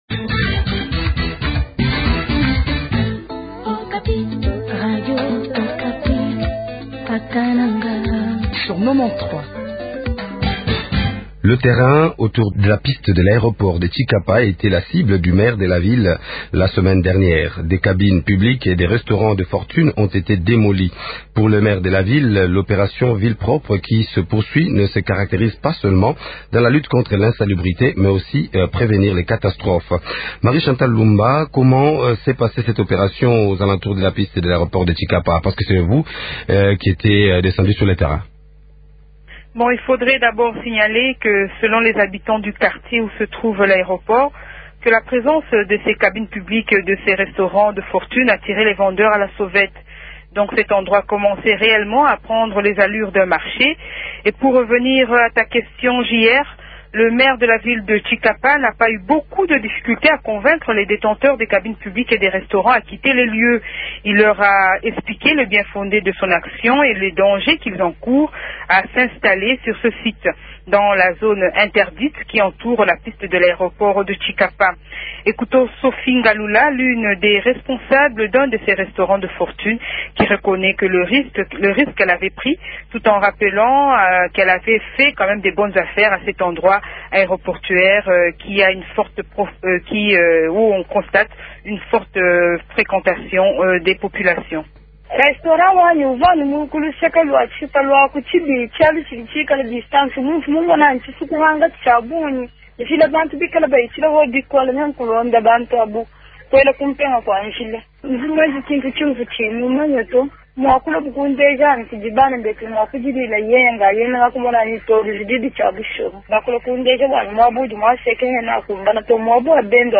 maire de la ville de Tshikapa